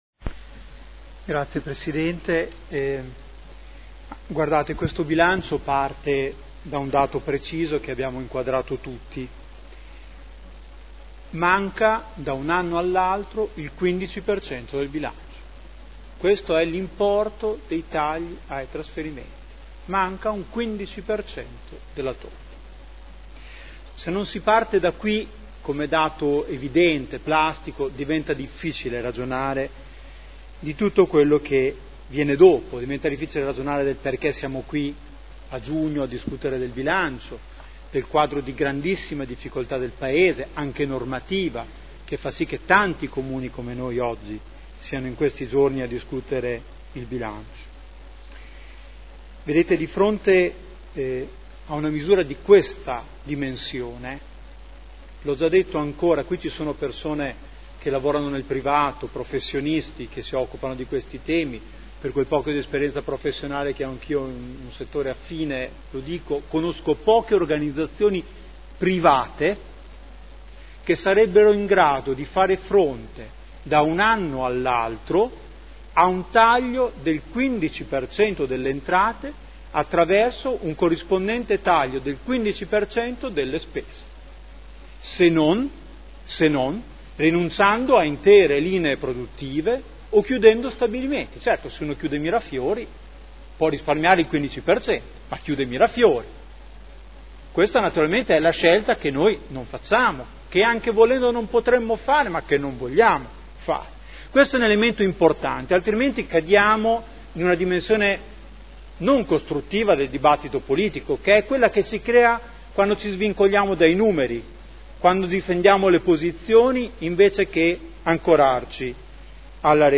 Seduta dell'11 giugno Replica su: relazione Previsionale e Programmatica - Bilancio Pluriennale 2012/2014 - Bilancio Preventivo per l'esercizio finanziario 2012 - Programma triennale dei lavori pubblici 2012/2014 – Approvazione